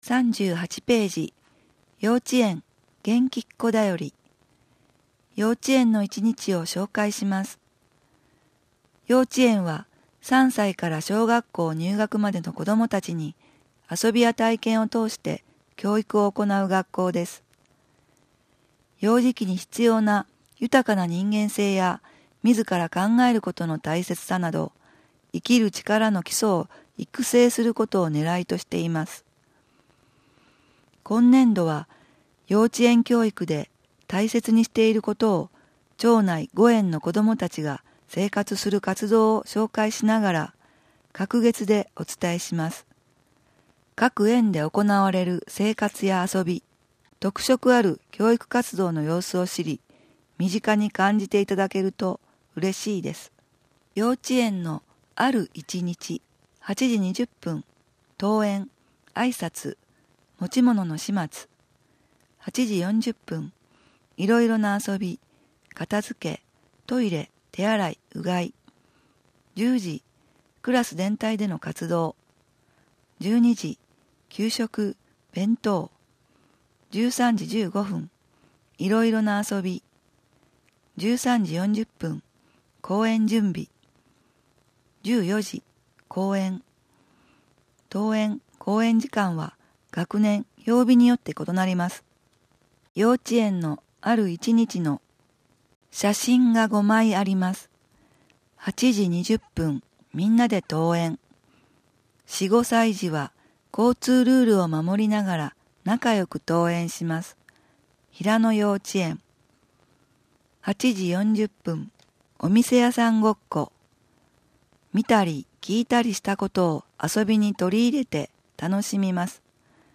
音訳広報たわらもと38〜39ページ (音声ファイル: 3.3MB)